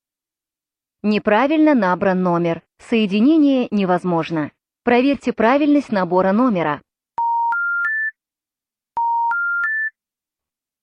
Голосовое сообщение для абонента набравшего номер не подпадающий под действующие правила.
wrongnumber.mp3